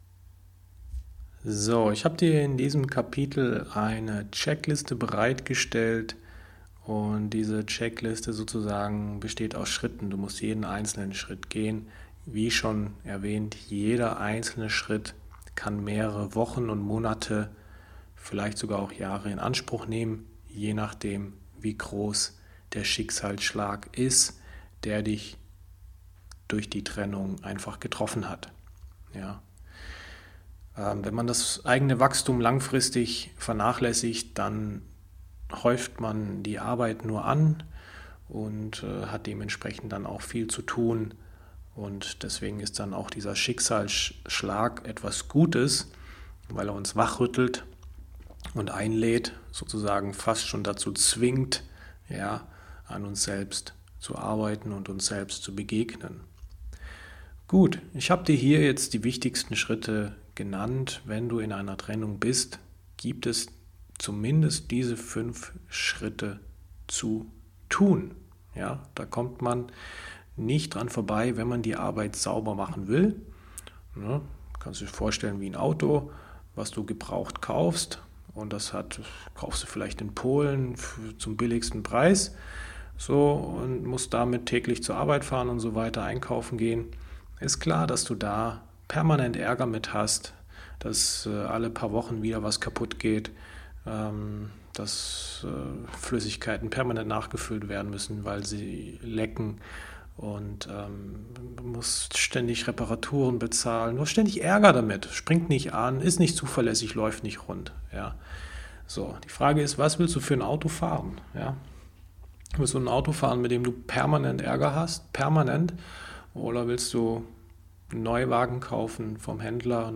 Audio-Hörbuch: Trennung verarbeiten und als Mann die Richtige finden